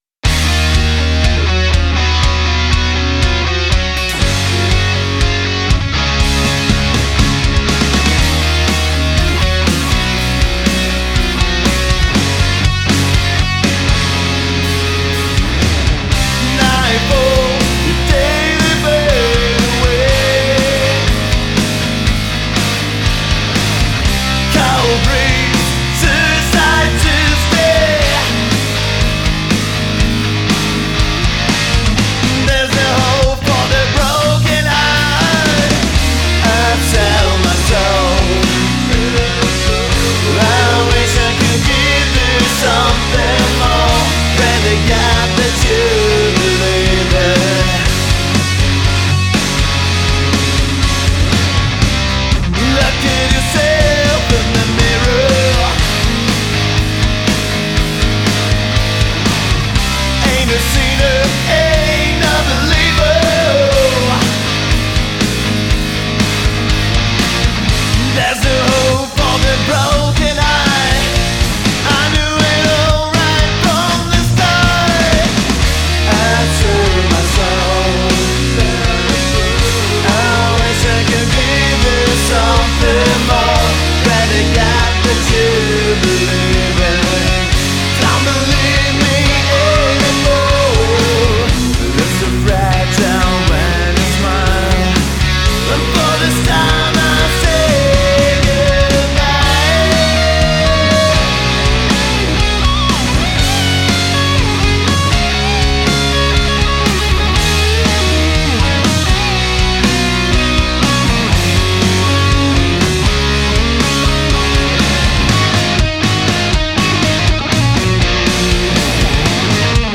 Genre: hardrock.